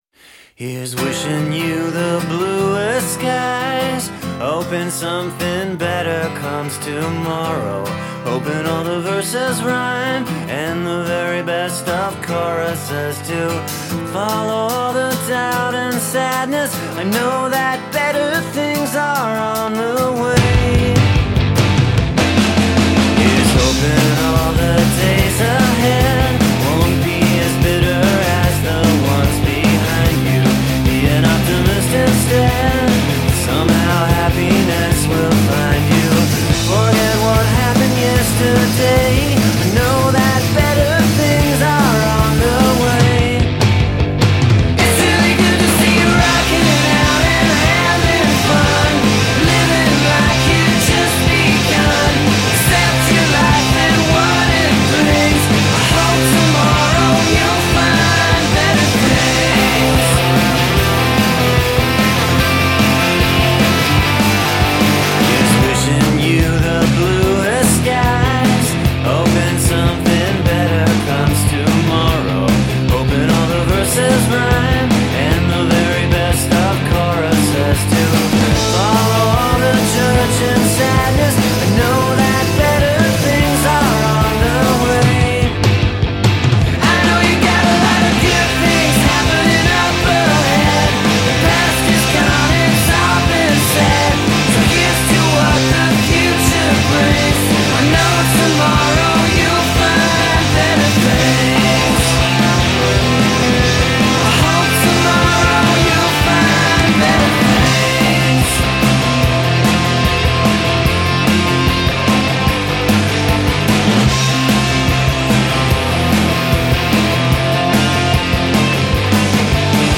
power poppers
turn up the volume